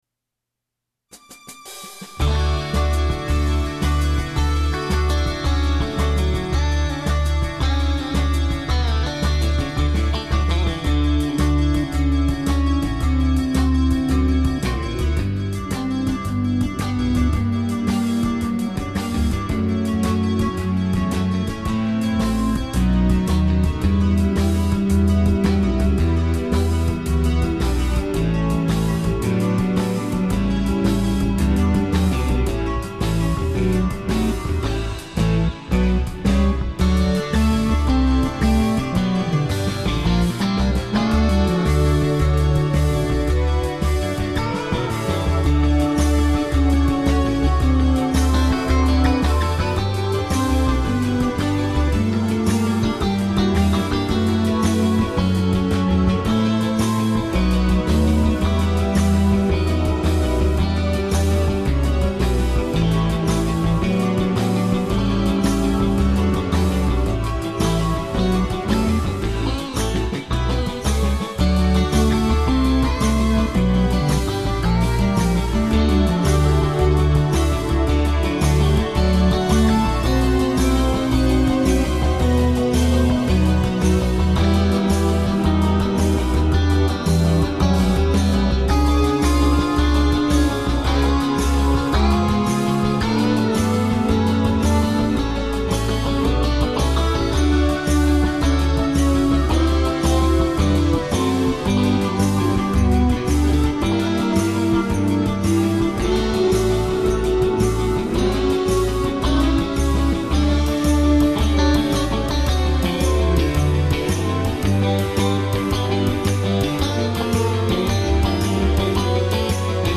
I wrote this on my acoustic guitar. This is a virtual instrument acoustic guitar. It’s out front at the start but soon gets kind of lost in the mix. I worked so hard at crafting the finger picking and now I can’t hardly hear it.